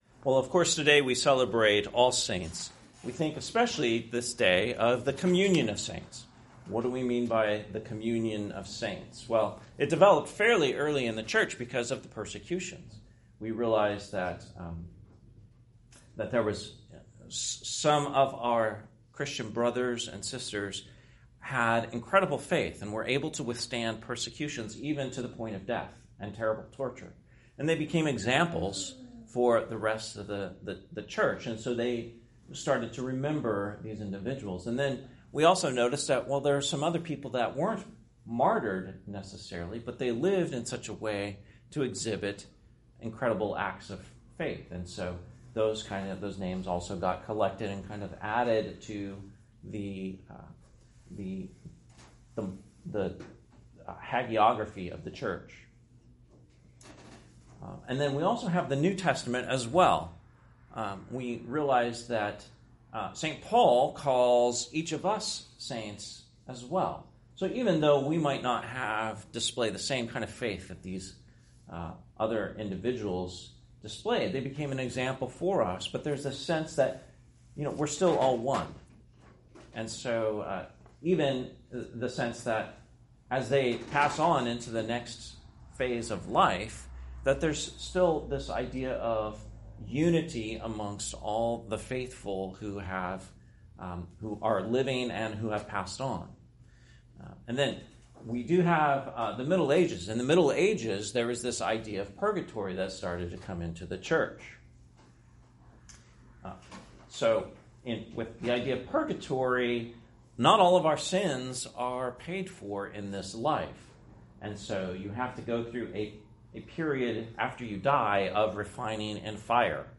Sermon, All Saints Day, 2023